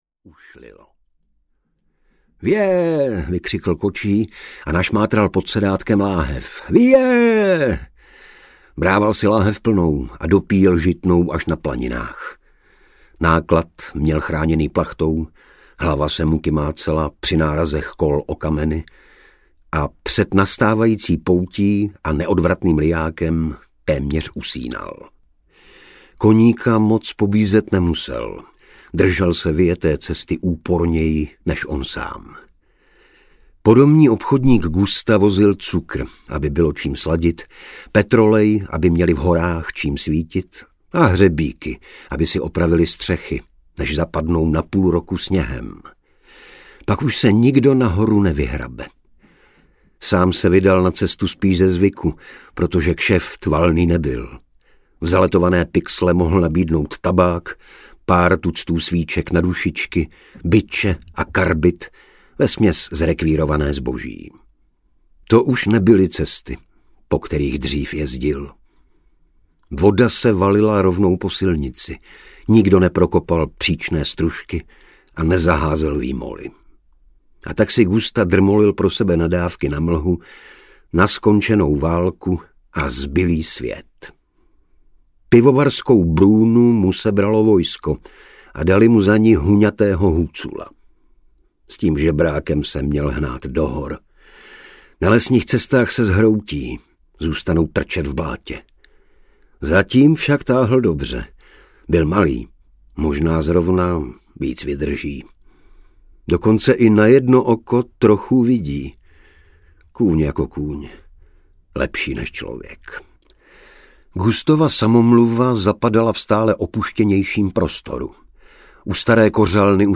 Zánik Samoty Berhof audiokniha
Ukázka z knihy
• InterpretIgor Bareš